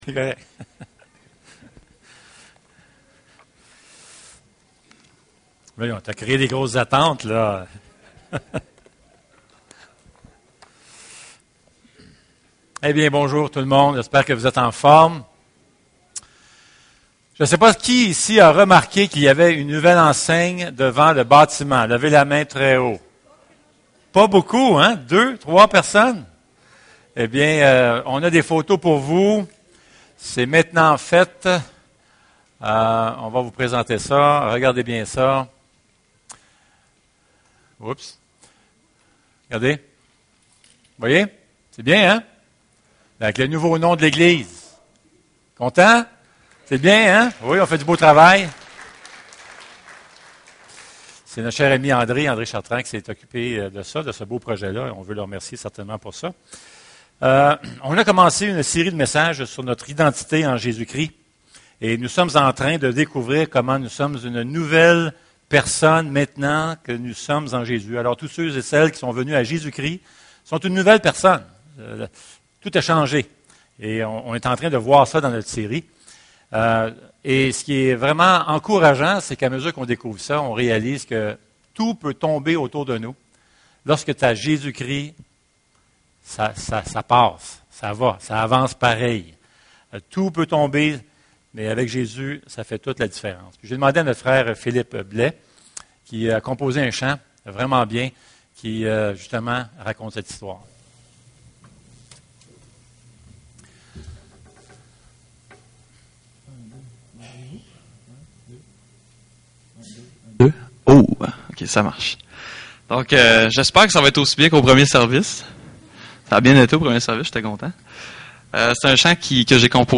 Passage: Ephésien 2:1-10 Service Type: Célébration dimanche matin 4eme message sur le thème de l'identités en Dieu.